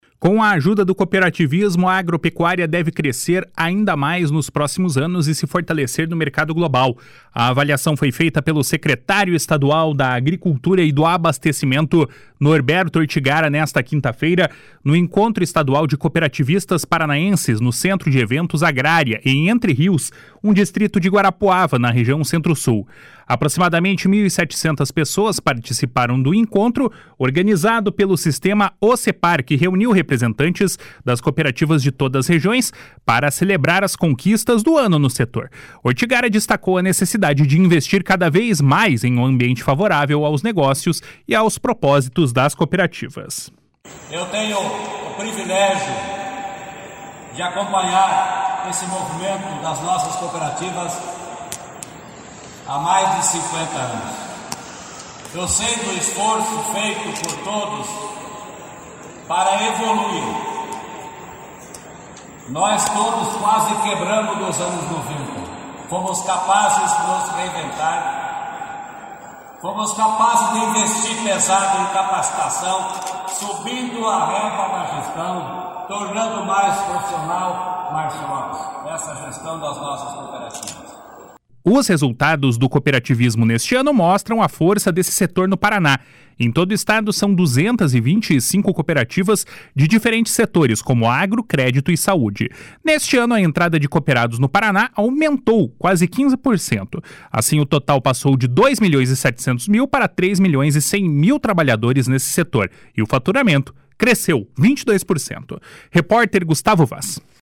Com ajuda do cooperativismo, a agropecuária do Paraná deve crescer ainda mais nos próximos anos e se fortalecer no mercado global. A avaliação foi feita pelo secretário estadual da Agricultura e do Abastecimento, Norberto Ortigara, nesta quinta-feira, no Encontro Estadual de Cooperativistas Paranaenses, no Centro de Eventos Agrária, em Entre Rios, Distrito de Guarapuava, na região Centro-Sul.
// SONORA NORBERTO ORTIGARA //